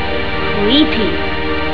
Here are some .wav files from Part 1 Acts I&II so I'll let the characters speak for themselves.